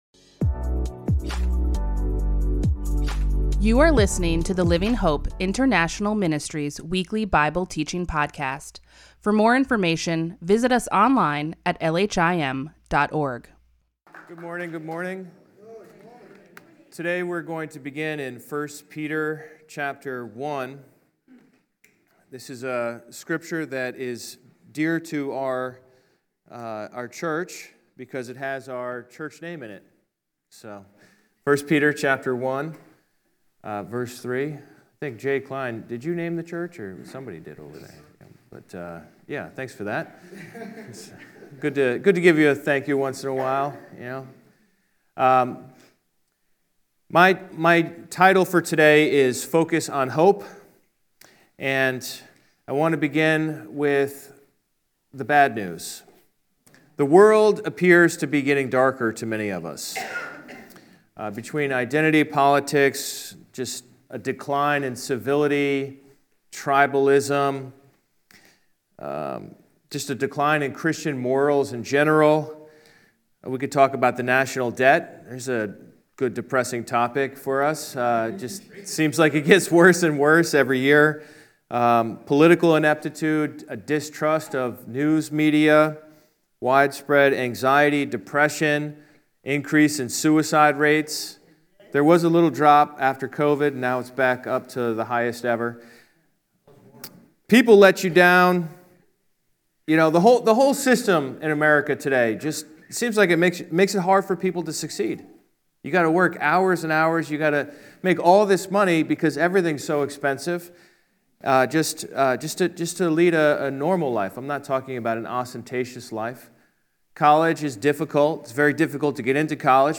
LHIM Weekly Bible Teaching